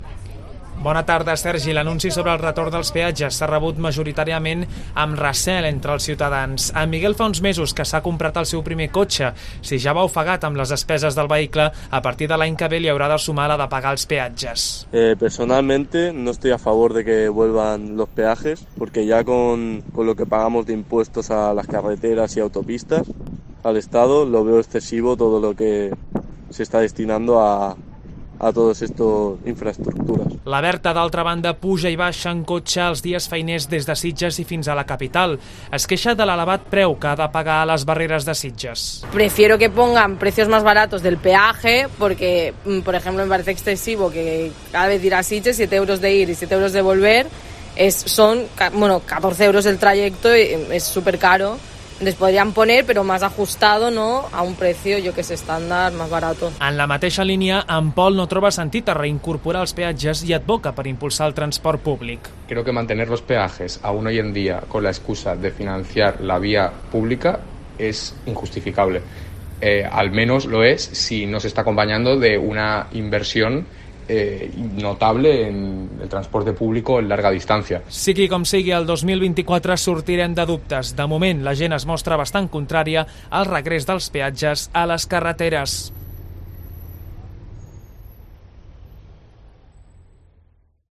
Declaraciones conductores sobre la imposición de los peajes en 2024